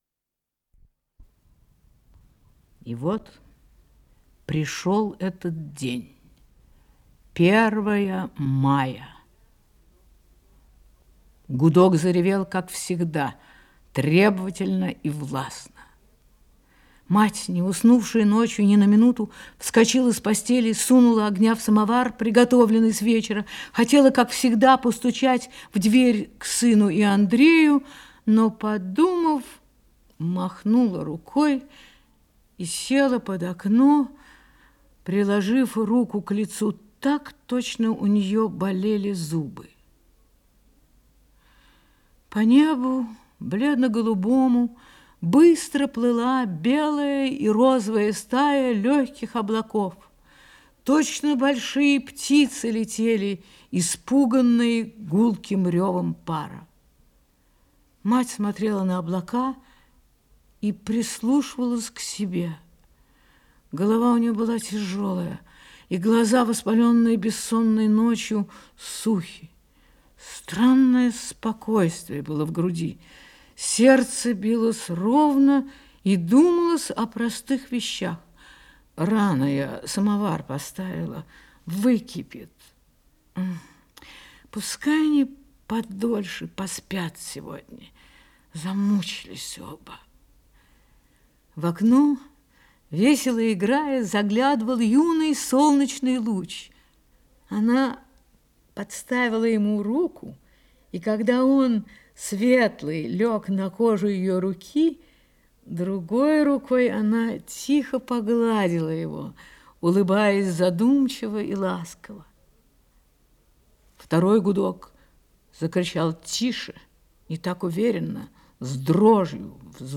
Исполнитель: Вера Марецкая - чтение